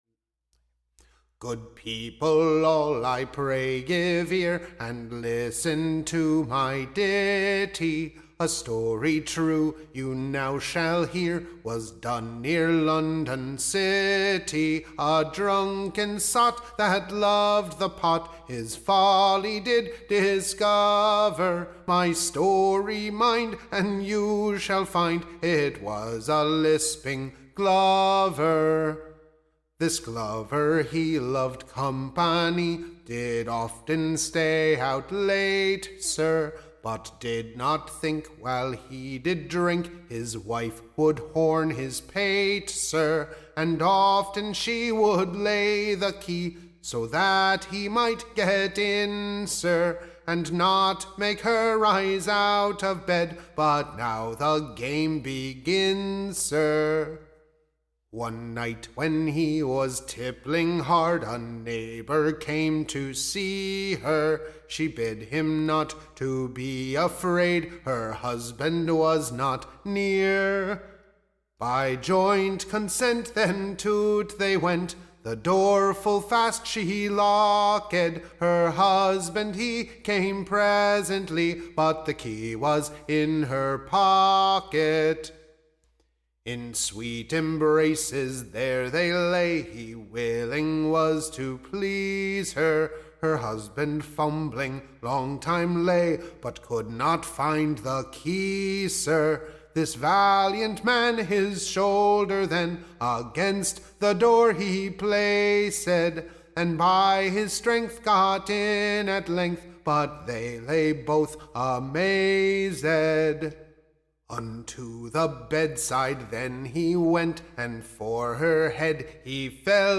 Recording Information Ballad Title Whose there Agen: / OR, / The 6-penny Cuckold of Shoreditch his Policy.